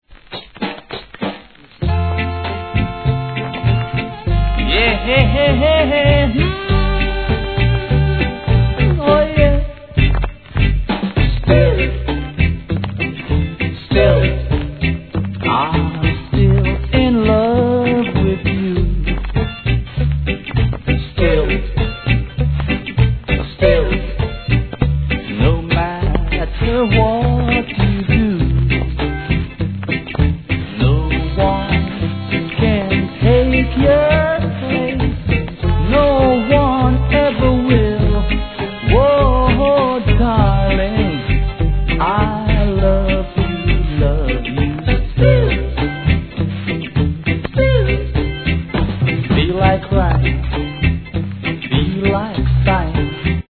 REGGAE
優しく歌い上げる素晴らしいLOVEソング♪